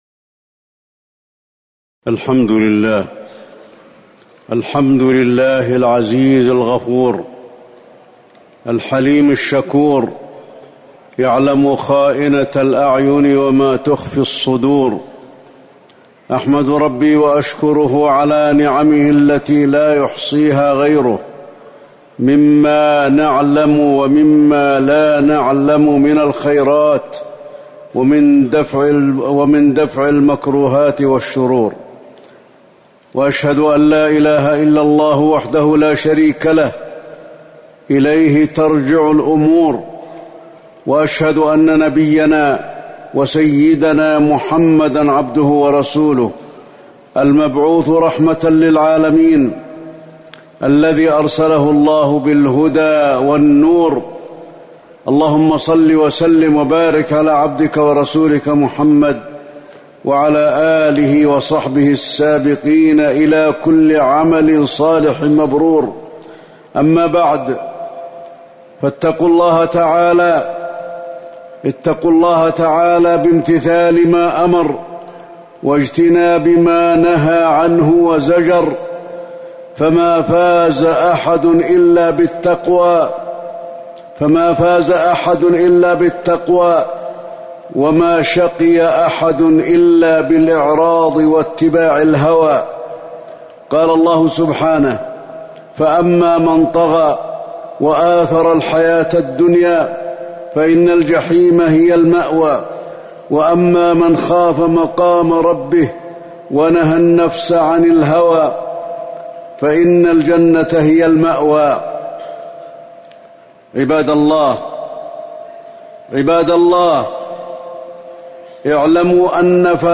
تاريخ النشر ٤ شوال ١٤٤٠ هـ المكان: المسجد النبوي الشيخ: فضيلة الشيخ د. علي بن عبدالرحمن الحذيفي فضيلة الشيخ د. علي بن عبدالرحمن الحذيفي الثبات على الطاعات وترك المنكرات